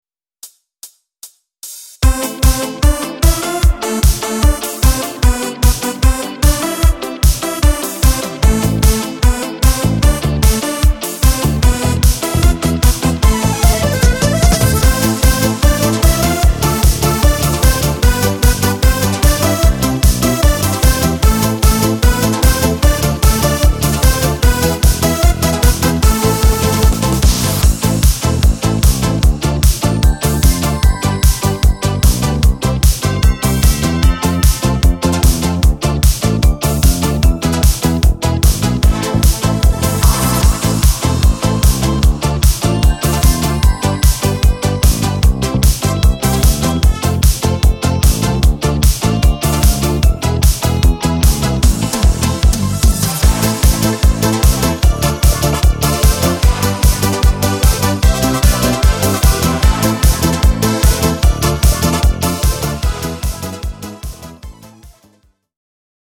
Kultowy utwór gatunku disco polo
w odświeżonej aranżacji